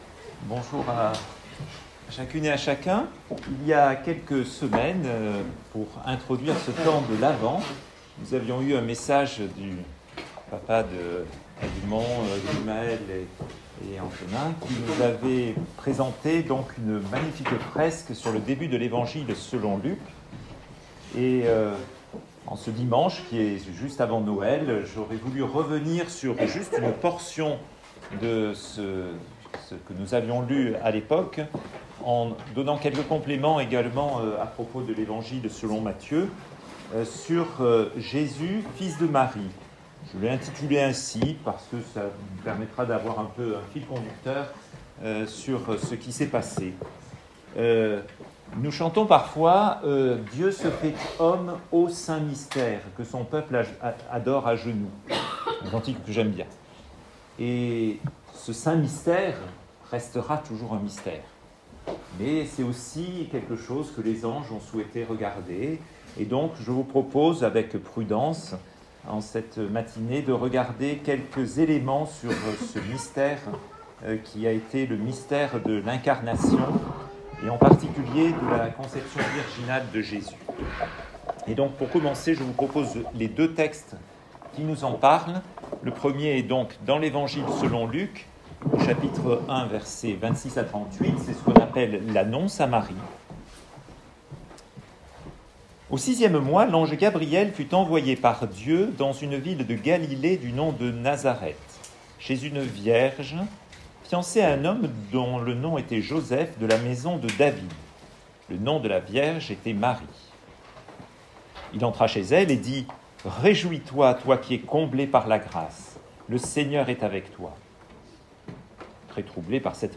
Abraham, la promesse d’un pays. Etude sur la genèse